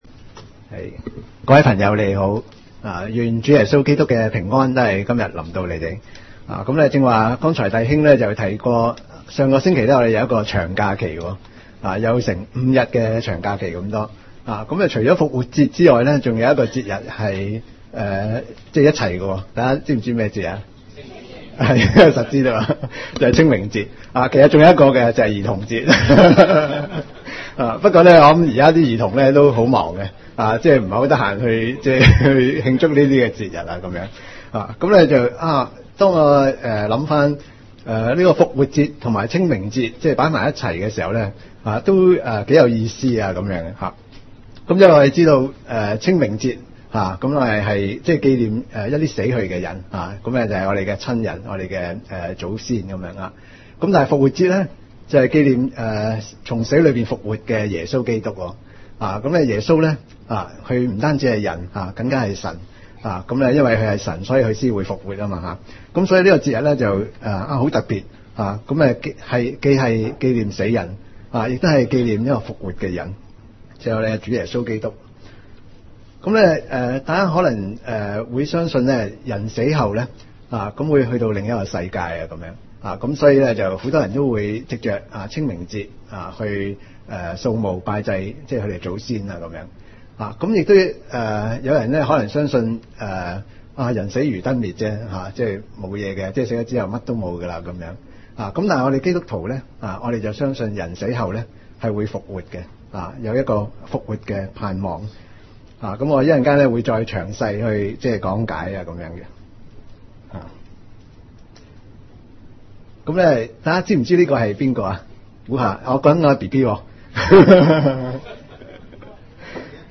福音聚會： 出死入生